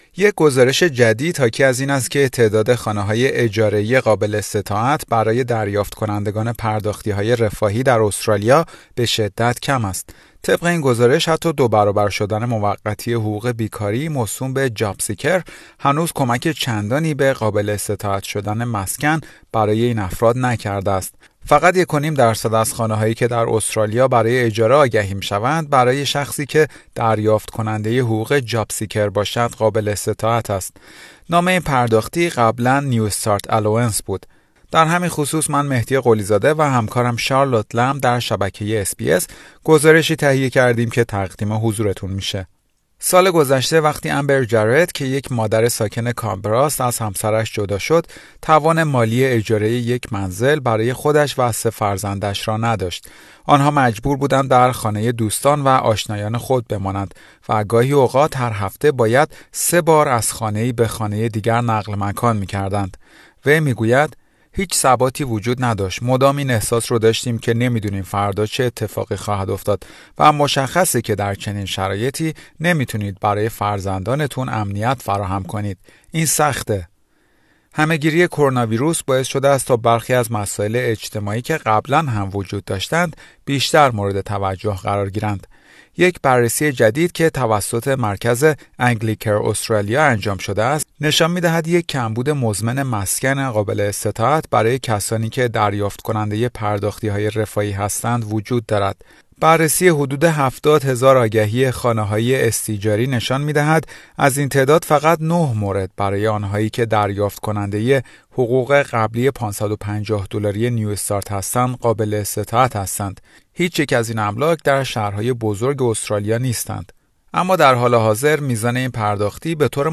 گزارش: بازار اجاره مسکن برای دریافت کنندگان پرداختی های رفاهی قابل استطاعت نیست